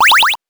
8 bits Elements
powerup_8.wav